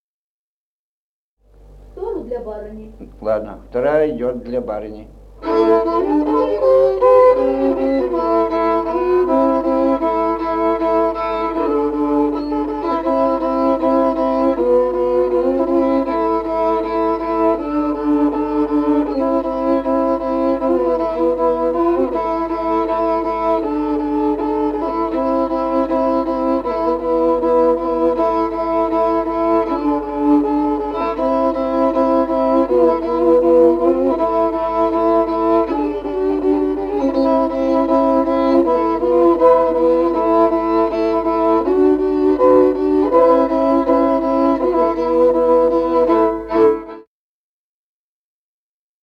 Музыкальный фольклор села Мишковка «Барыня», партия 2-й скрипки.